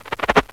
SPARKS.WAV